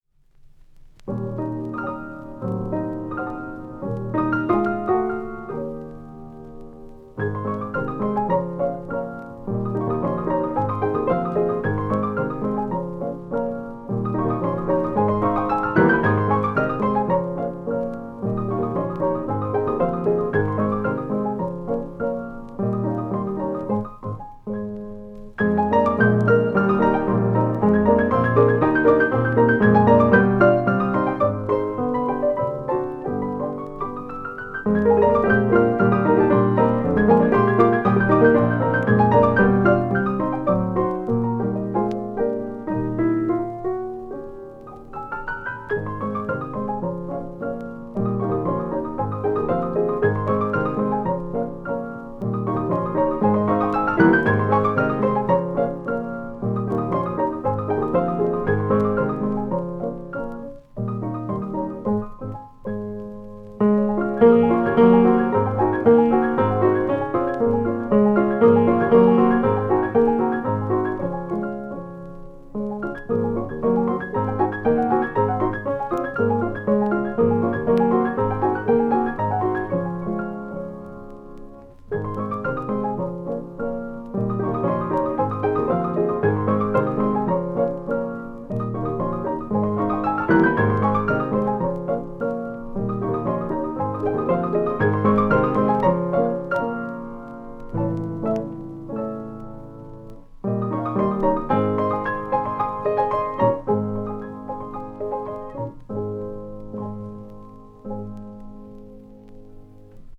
Sarau de Sinhá – Valsa